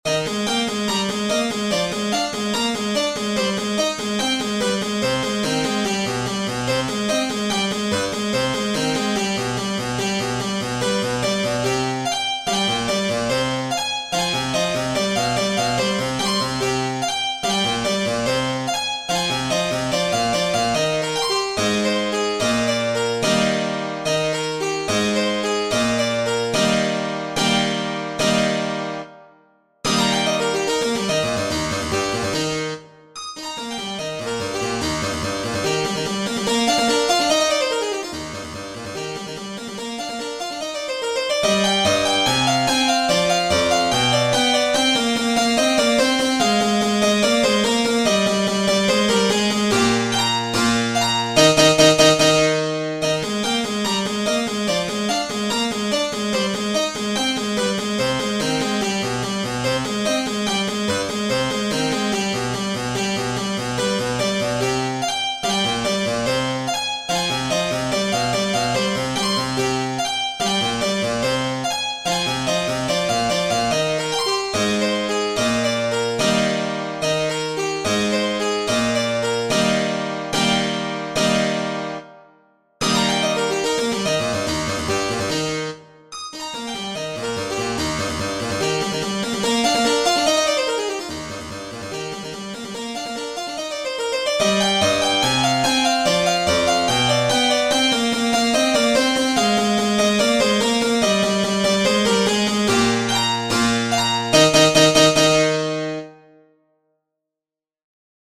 Bagatelle 14 and 15 - Piano Music, Solo Keyboard
Here is my 14th bagatelle in e flat major, enjoy!